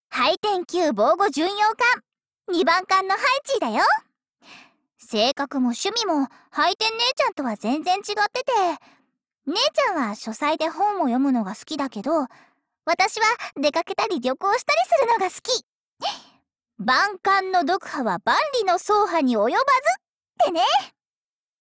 日配：伊达 朱里纱 / 伊達 朱里紗 / だて ありさ / Arisa Date / 个人推特 / 事务所个人介绍页